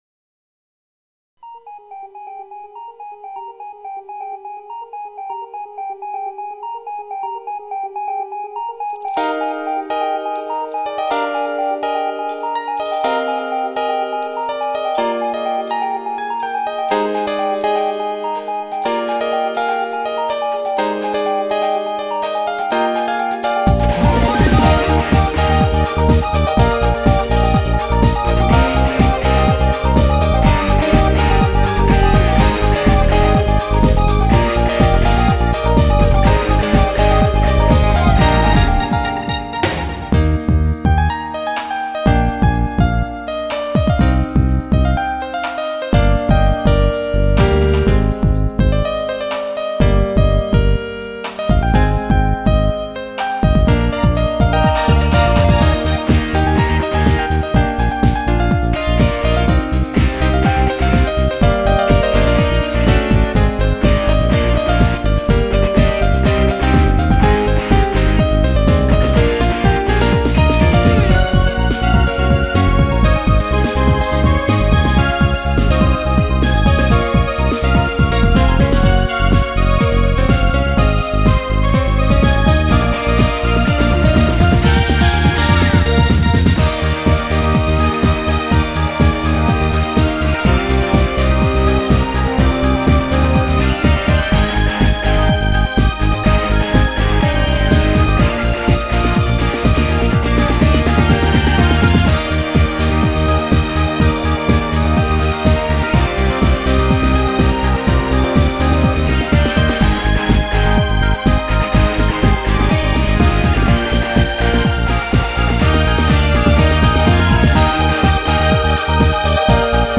Music Studio Standard   Cherry Fusion/Pop
今回は明るめのフュージョン系ポップです。
ミキシングの仕方も、音源が変わったことで少し変えてみました。